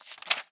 bookturn2.ogg